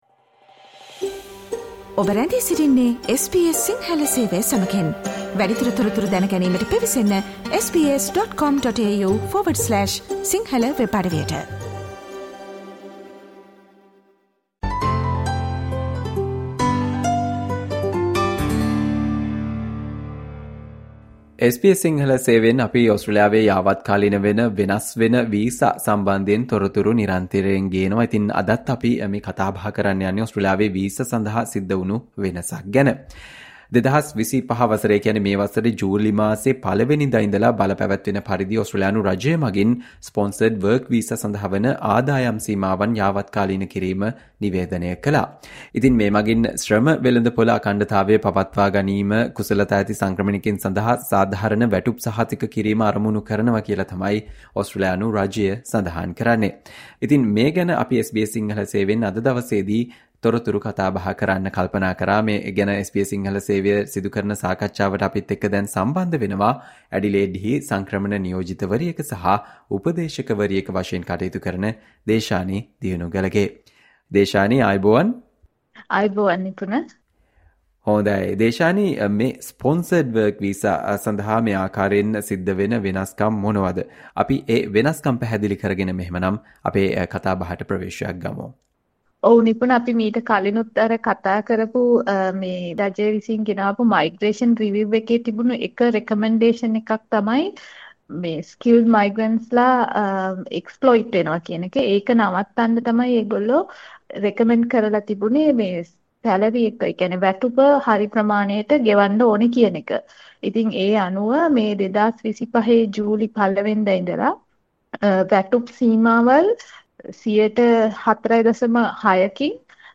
ඕස්ට්‍රේලියාවේ Sponsored work visa සඳහා රජය වැටුප් සීමා යාවත්කාලීන කිරීම සහ ඒවා අයදුම් කරන්නන් හට බලපාන ආකාරය සම්බන්ධයෙන් SBS සිංහල සේවය සිදු කල සාකච්චාවට සවන්දෙන්න